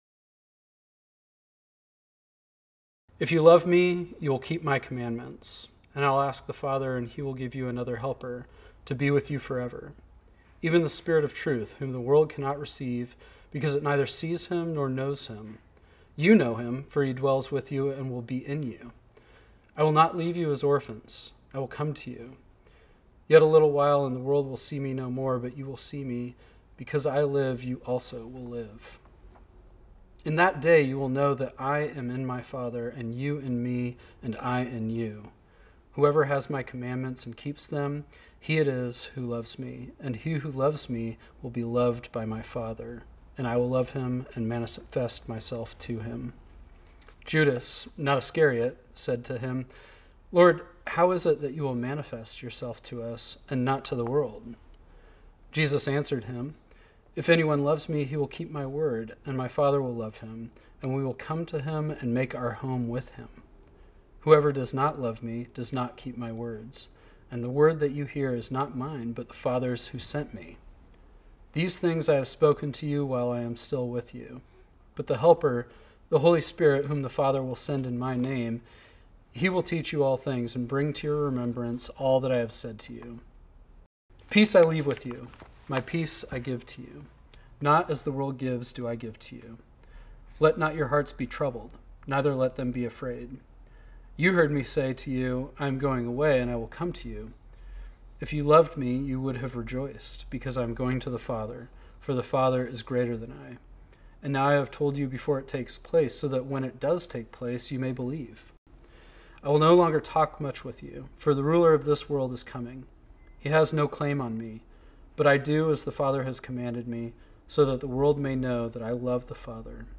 This sermon was originally preached on Sunday, May 24, 2020.